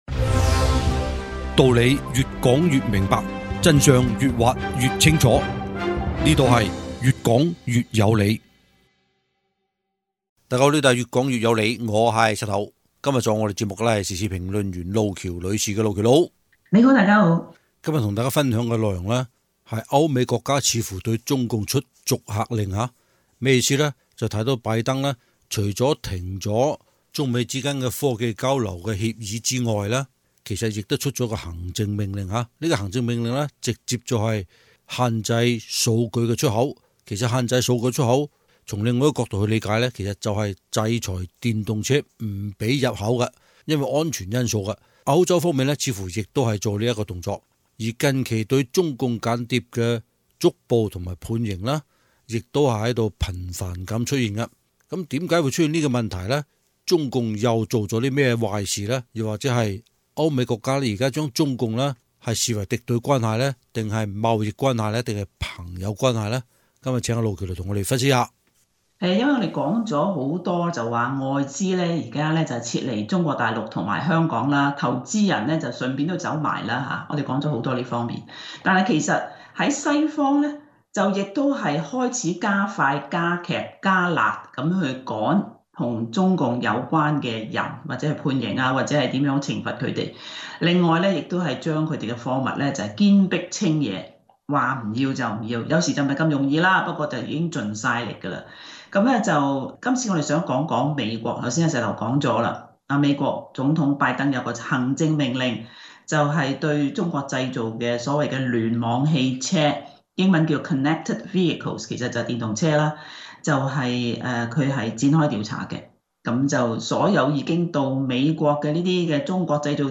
嘉賓